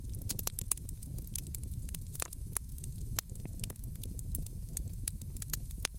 Campfire Crackling
A warm campfire crackling and popping with occasional wood shifts and ember sparks
campfire-crackling.mp3